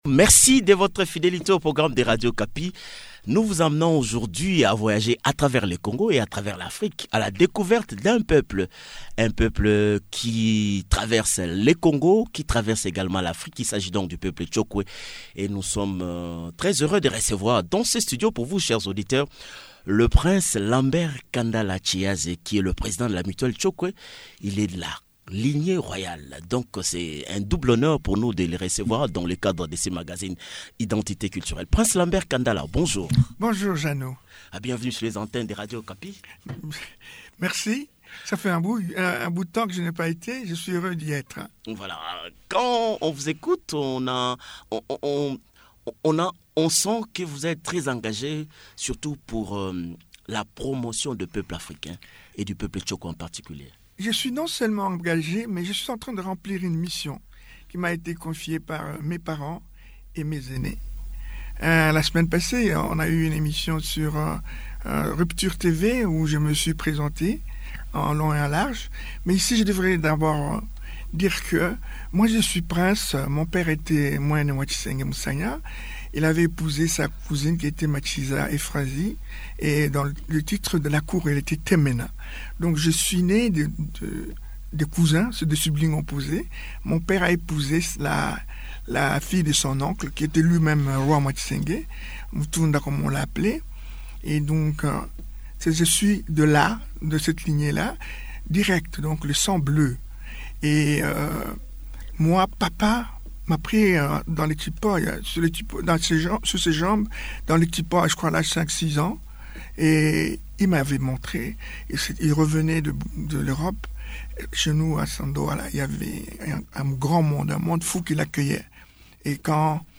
Il a fait cette recommandation dans un entretien accordé à Radio Okapi.